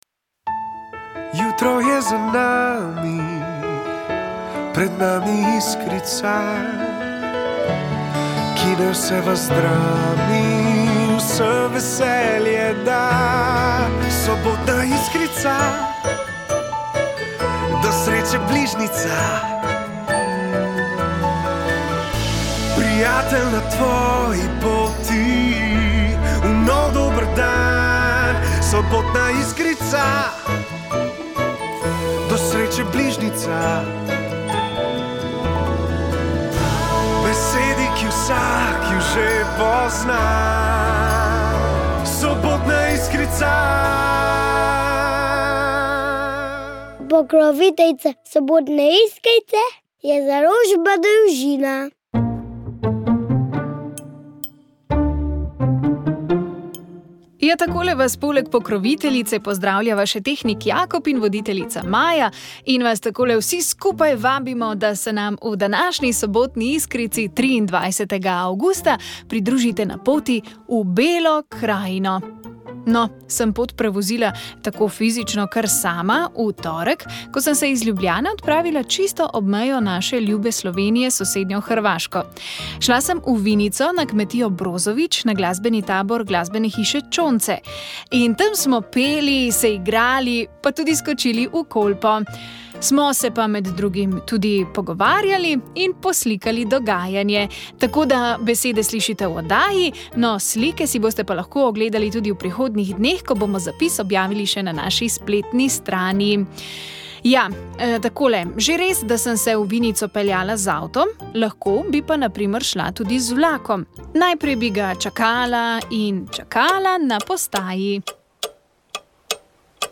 specialist družinske medicine, ki je odgovarjal na vprašanja poslušalcev.